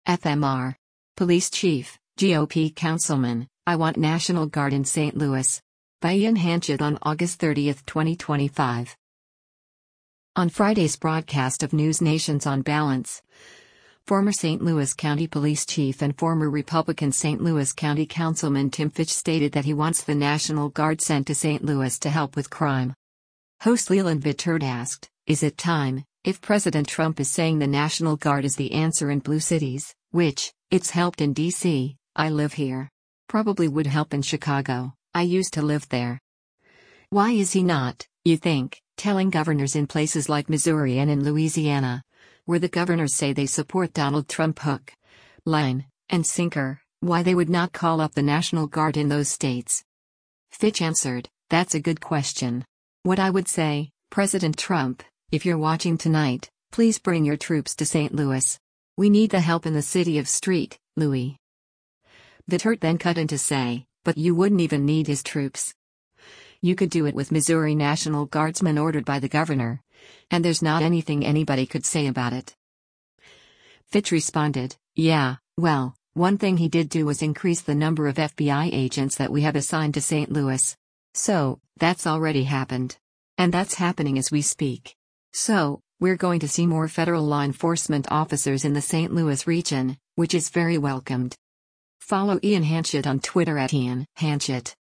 On Friday’s broadcast of NewsNation’s “On Balance,”  former St. Louis County Police Chief and former Republican St. Louis County Councilman Tim Fitch stated that he wants the National Guard sent to St. Louis to help with crime.